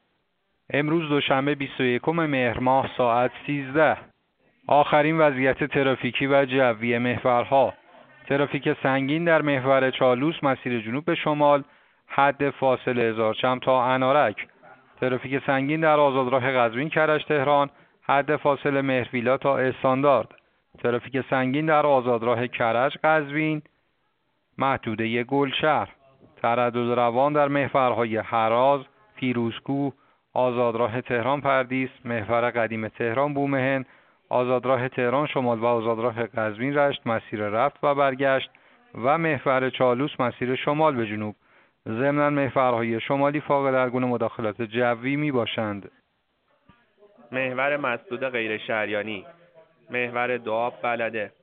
گزارش رادیو اینترنتی از آخرین وضعیت ترافیکی جاده‌ها ساعت ۱۳ بیست‌ویکم مهر؛